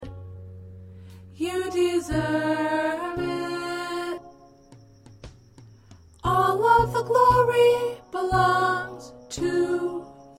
Practice tracks